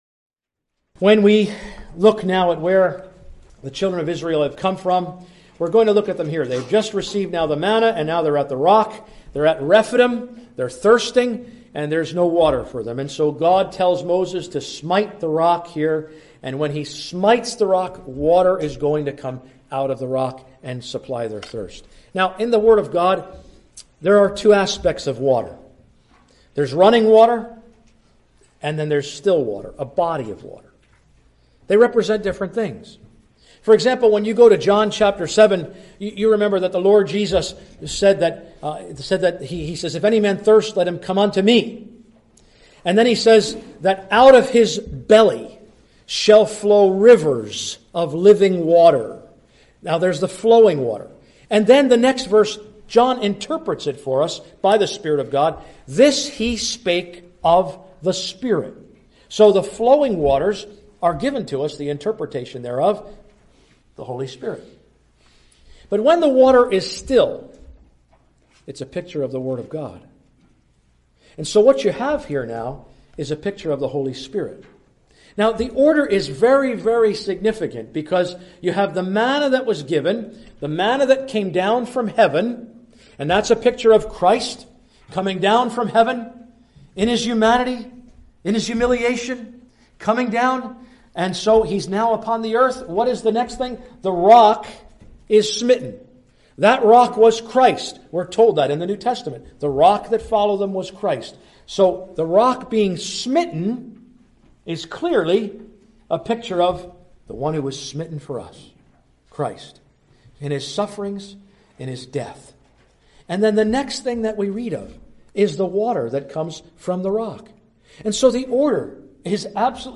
Amalek, a picture of the flesh; Korah, a picture of the world; and Balaam, a picture of the devil. Readings: Exod 17:1-6, Num 16:1-50, 17:1-13, and various readings from Chs 22, 23 and 24. (Recorded in Marion Gospel Hall, Iowa, USA)